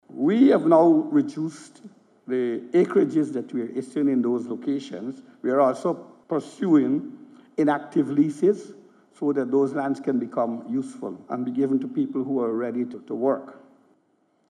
His made this comment during a keynote address at the closing ceremony of the Sustainable Land Development and Management Project at the Pegasus Suites.